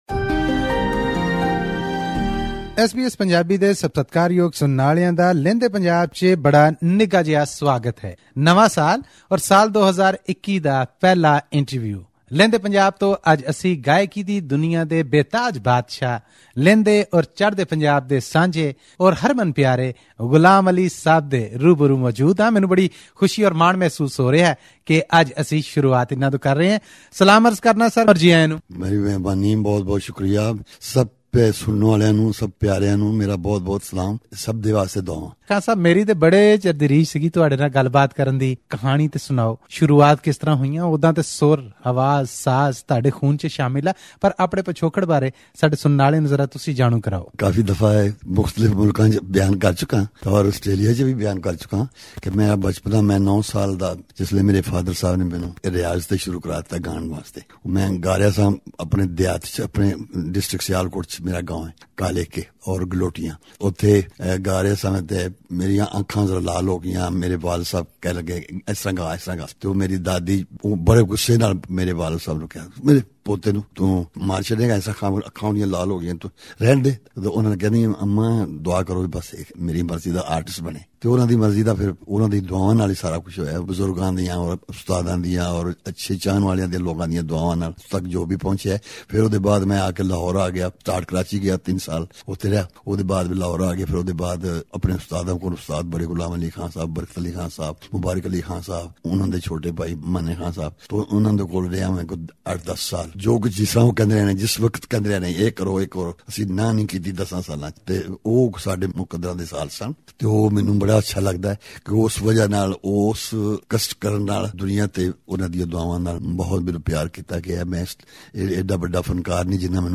C lick on this audio link to he ar the full interview with Ghulam Ali.